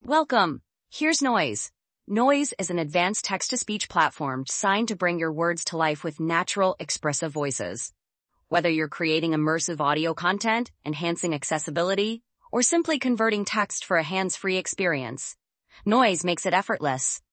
Noiz AI是一个为客户提供与真人无异、极具表现力语音合成能力的产品。
抖音爆款语音： 他，是汉朝宫中默默无闻的普通宫女，却以一己之力促成了汉匈之间的和平，被后世传颂为千古佳话。